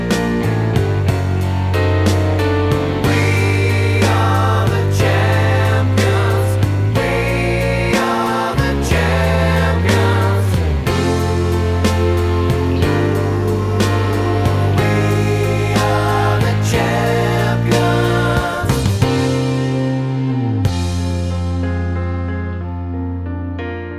One Semitone Down Rock 3:08 Buy £1.50